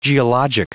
Prononciation du mot geologic en anglais (fichier audio)
Prononciation du mot : geologic